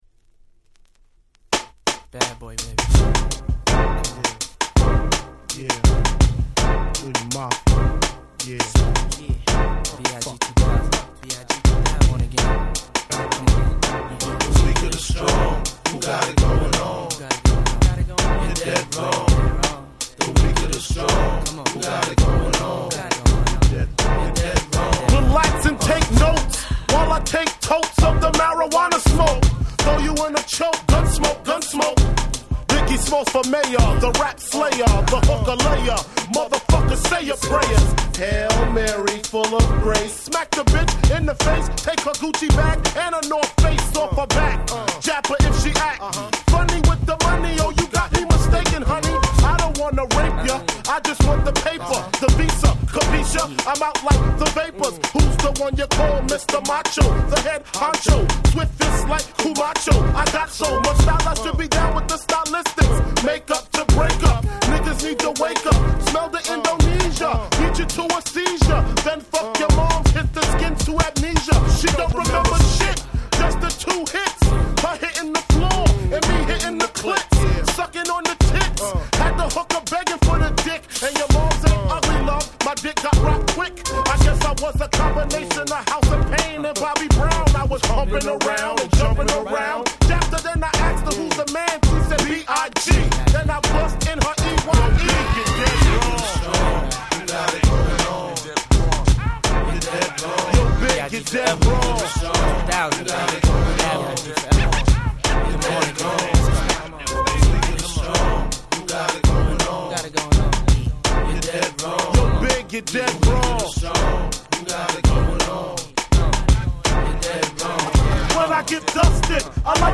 99' Smash Hit Hip Hop !!
Late 90's Hip Hop Classic !!
Boom Bap